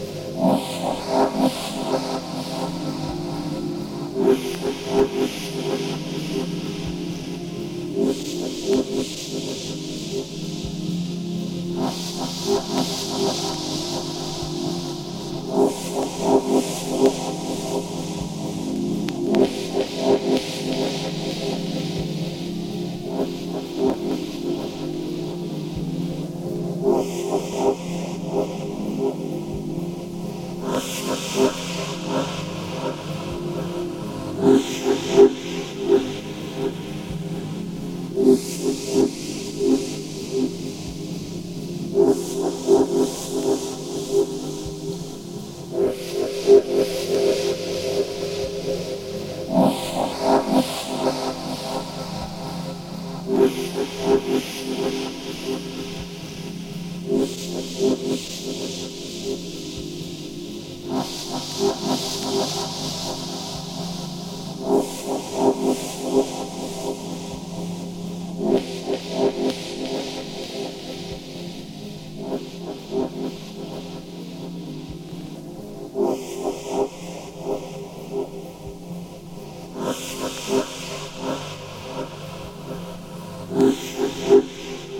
シンセのプロダクションの奥行きと余白が最早視覚にまで現れそうな、恐ろしい立体感です。